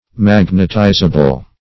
Magnetizable \Mag"net*i`za*ble\, a.